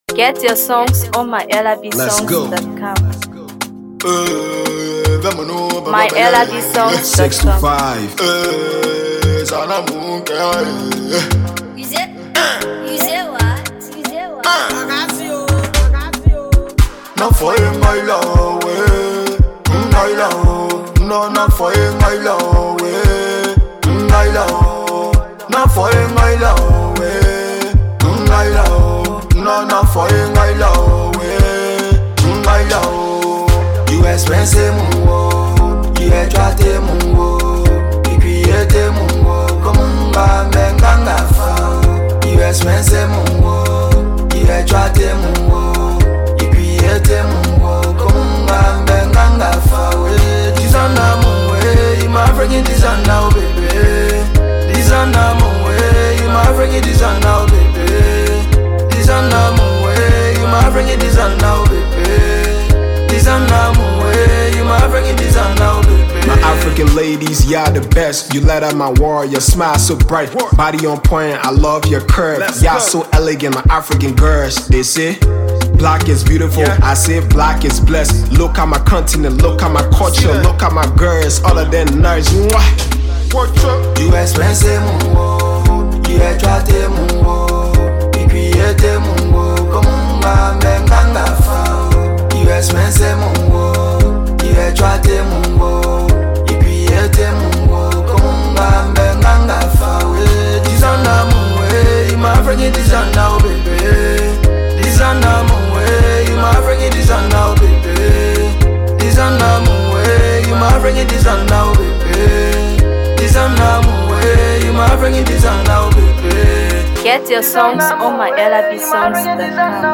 Afro Pop